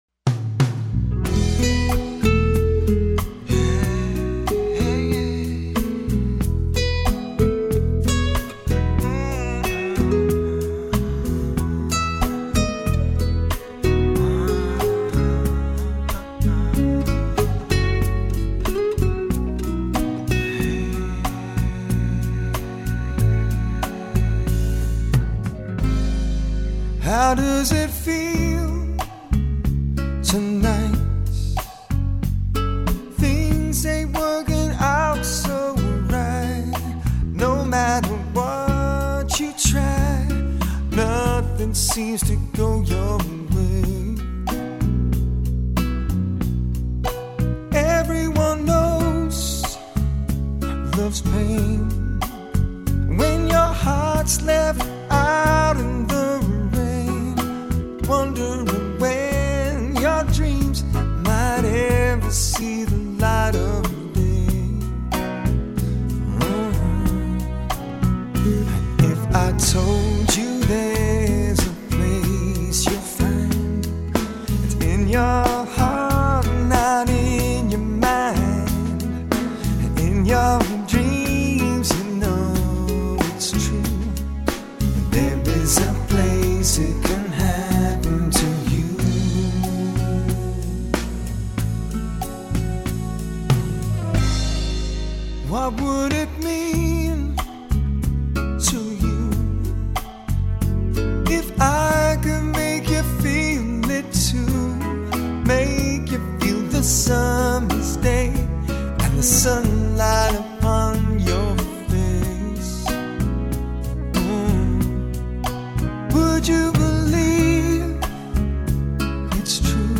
Smooth Jazz
embraces some traditional elements of jazz